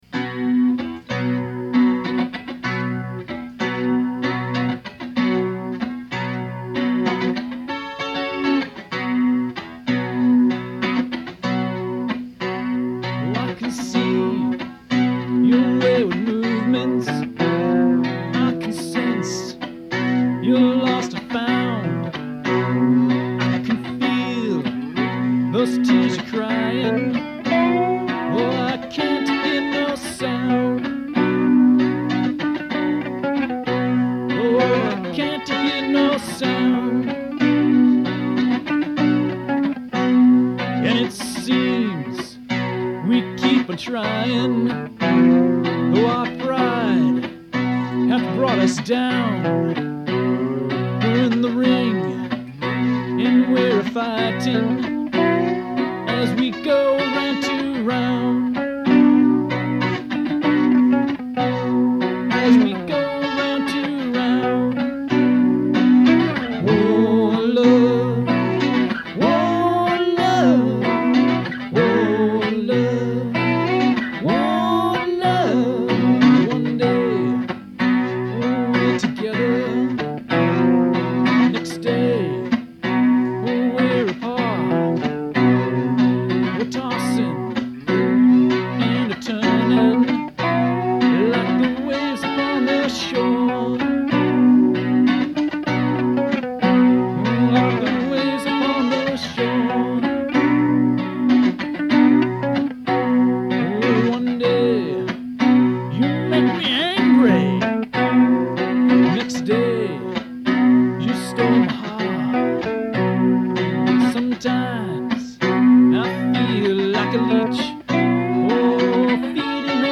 Guitar, Vocals